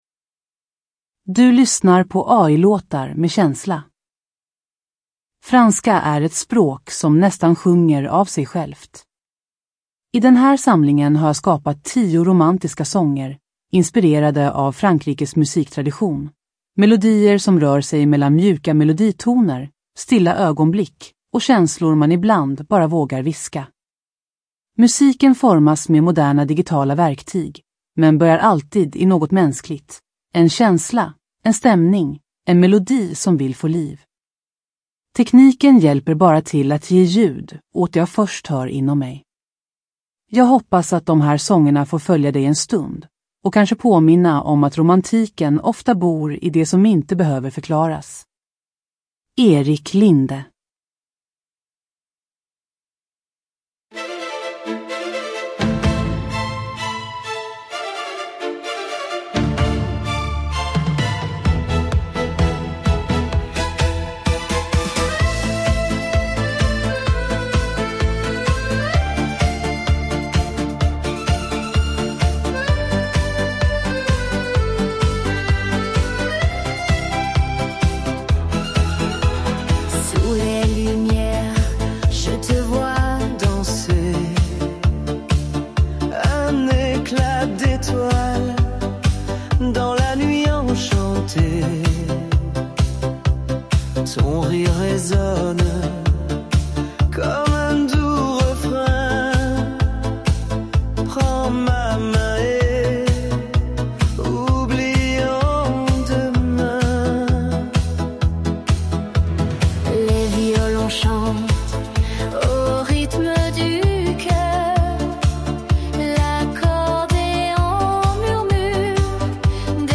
sjungna på franska